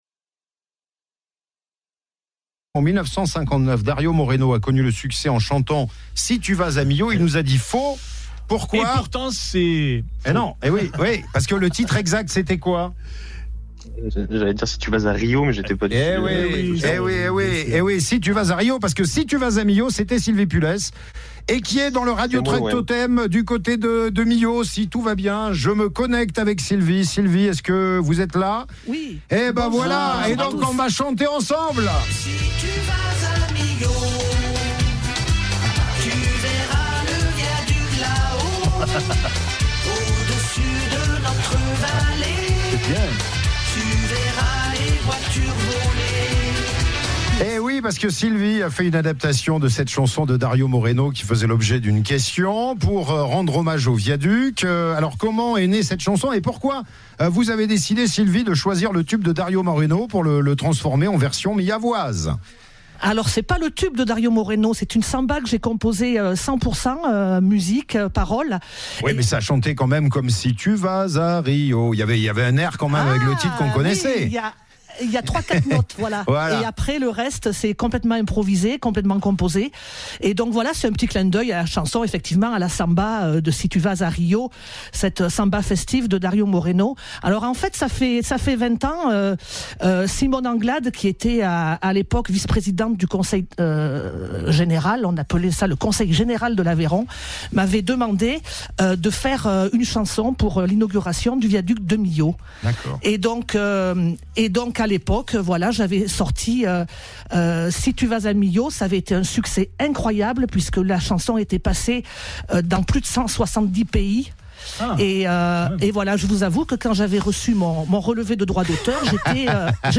en direct du Viaduc de Millau pour les 20 ans de l’édifice dans « Martin Bonheur »sur radio Totem :